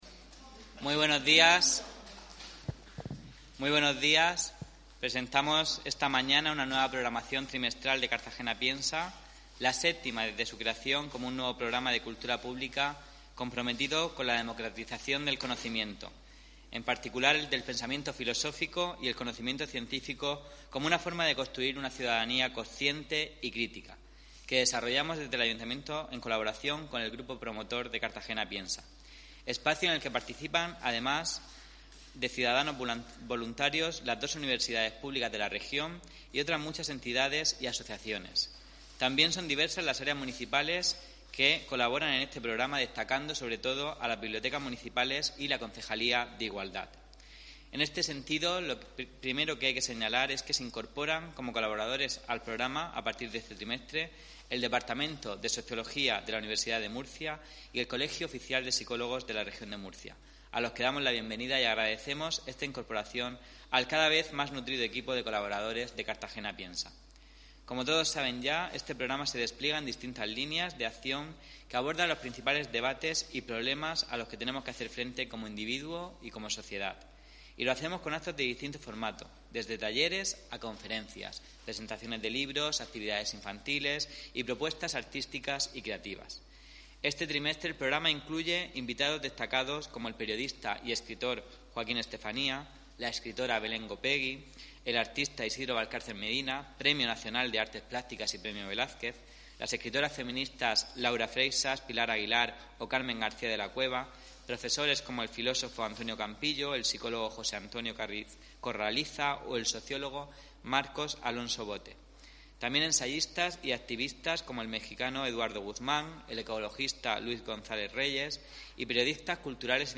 Audio: Presentaci�n de la programaci�n de Cartagena Piensa (MP3 - 10,13 MB)